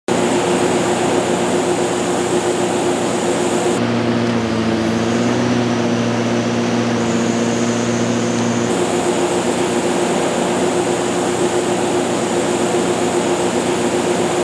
200kW Olympian Diesel Generator For sound effects free download